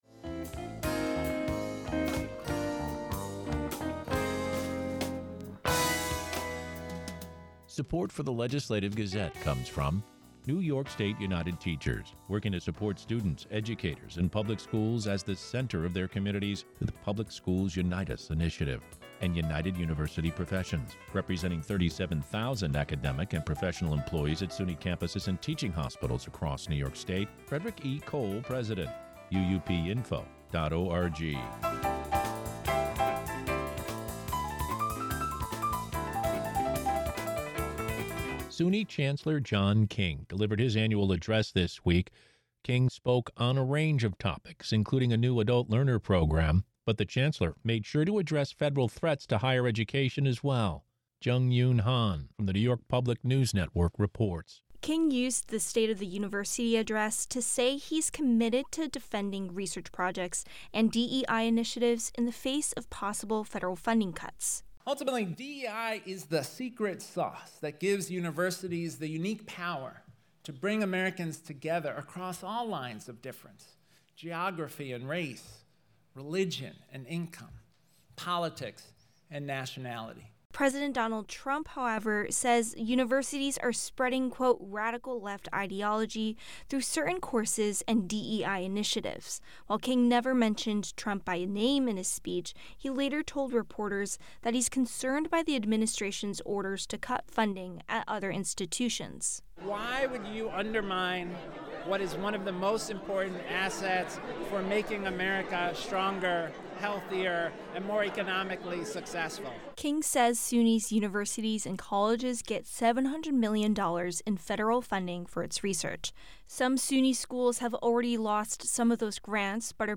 The Legislative Gazette - SUNY Chancellor John King delivers his annual address